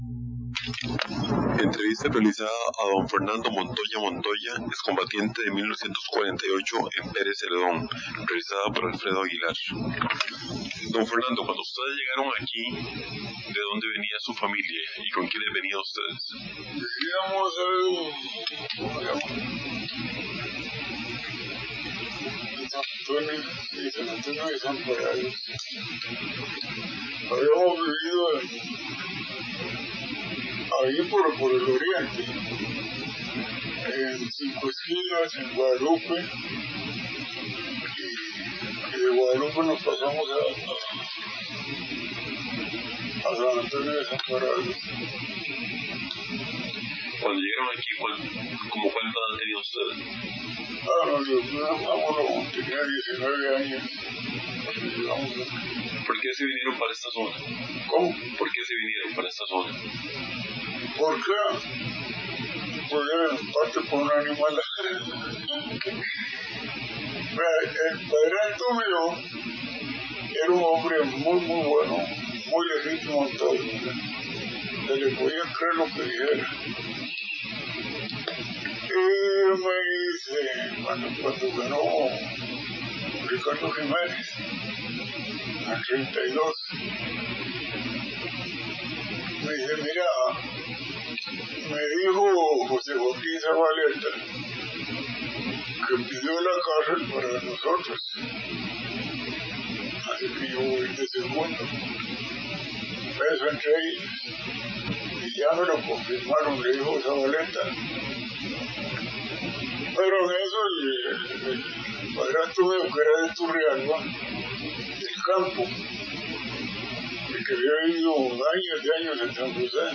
Notas: Casete de audio y digital